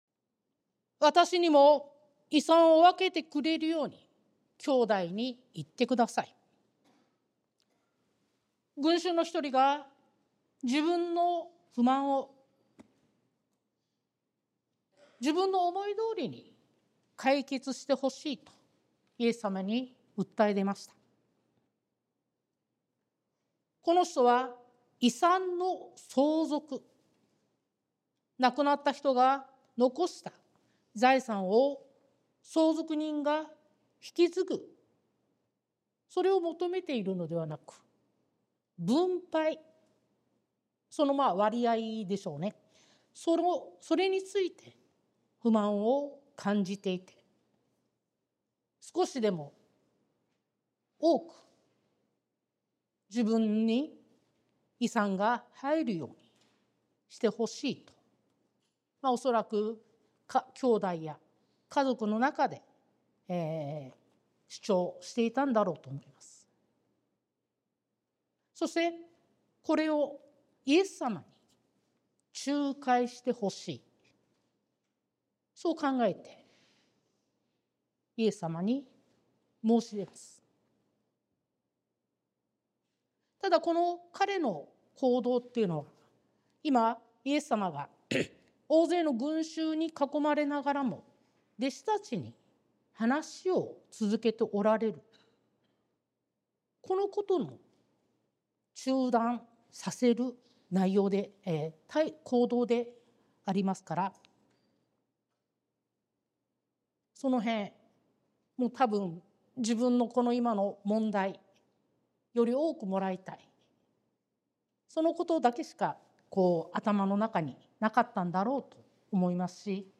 sermon-2024-11-24